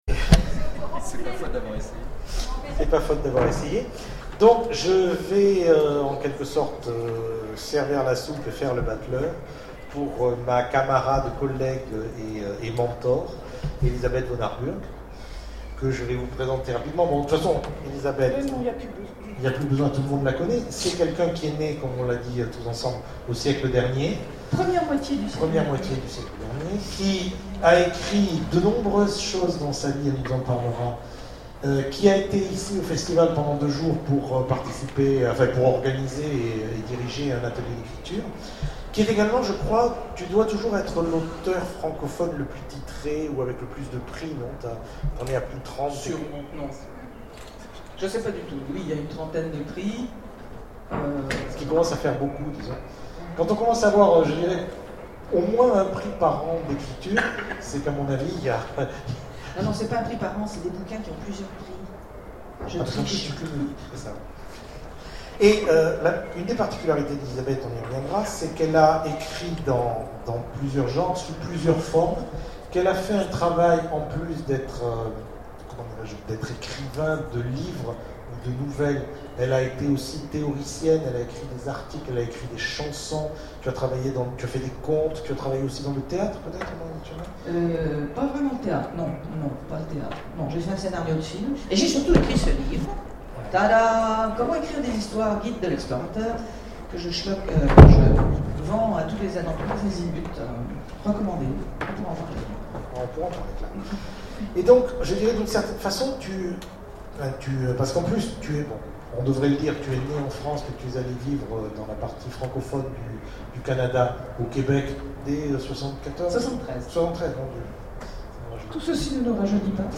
Imaginales 2012 : Conférence rencontre avec Elisabeth Vonarburg
Conférence